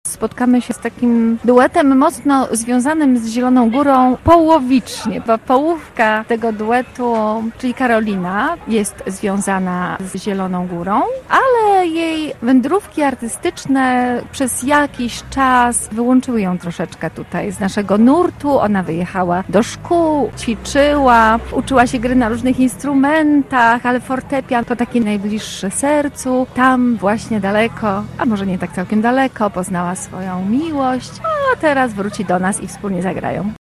Do udziału w koncercie zachęcała w naszym wakacyjnym studio Radia Zielona Góra „Lato w mieście”